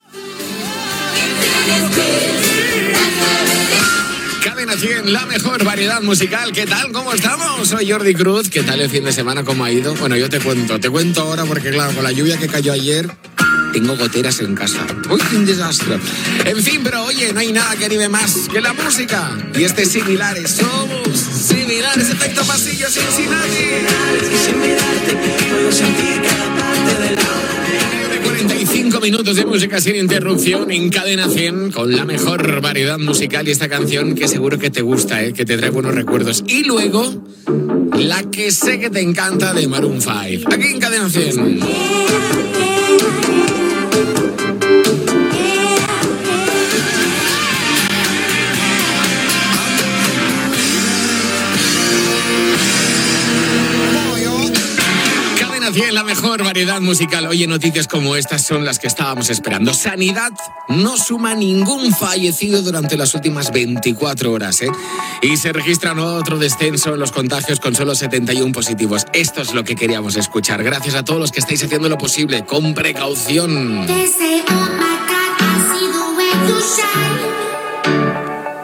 Tema musical, salutació amb un comentari sobre les goteres a casa, presentació de temes musicals, identificació de la ràdio i dades de la pandèmia de la Covid 19.
Musical